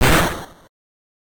adds missing several sound effects
Battle damage normal.ogg